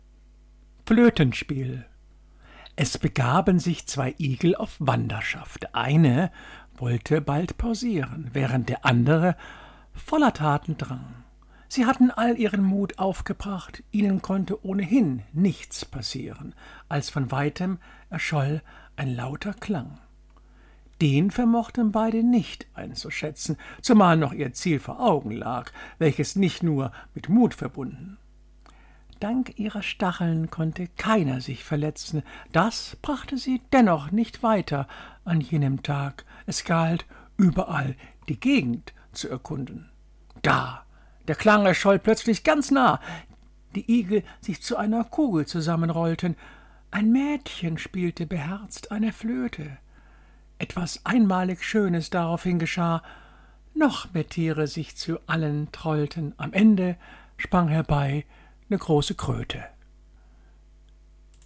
Gedichte – rezitiert
Insofern möchte ich hierbei so manche Gedichte für euch rezitieren.